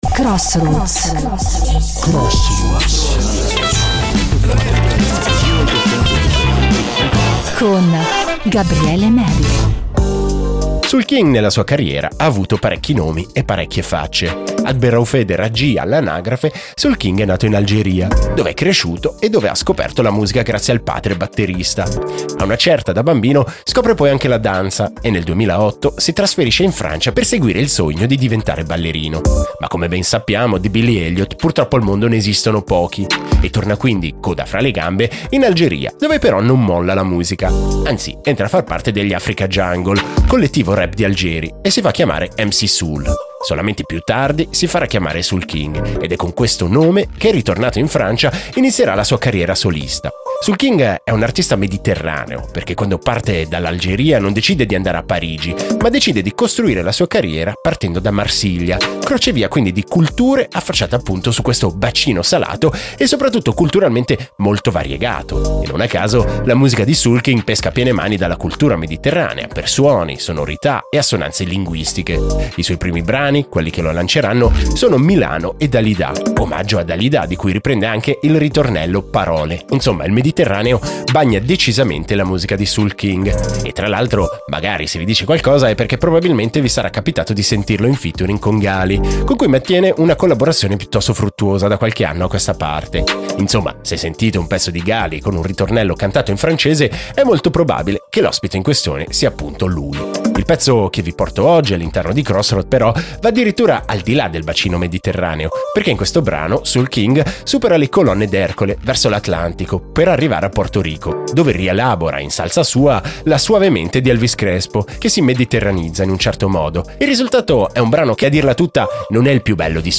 remix mediterraneo
classico merengue